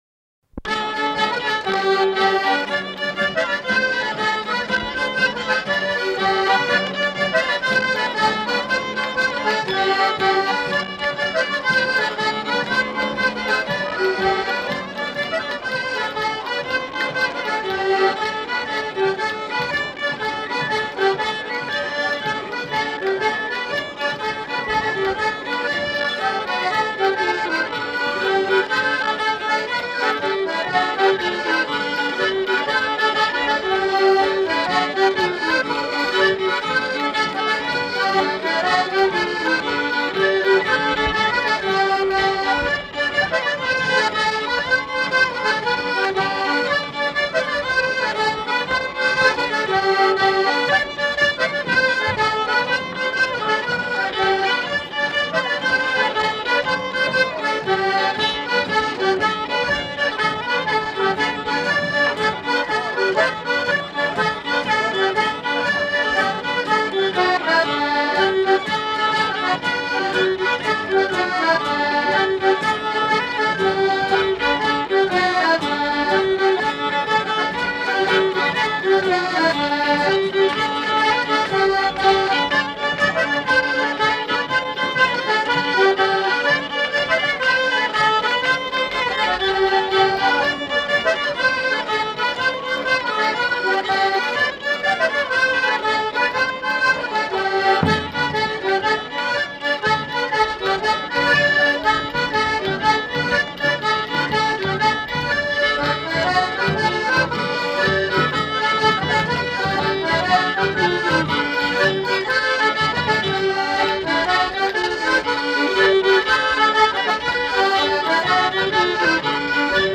Rondeau
Orchestre du Conservatoire occitan (ensemble vocal et instrumental)
Aire culturelle : Savès
Genre : morceau instrumental
Instrument de musique : accordéon diatonique ; violon
Danse : rondeau